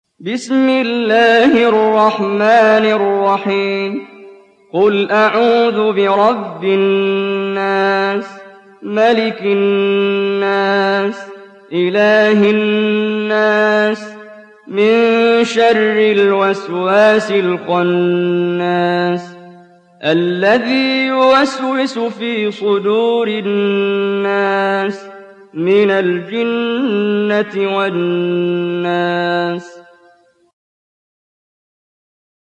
دانلود سوره الناس mp3 محمد جبريل (روایت حفص)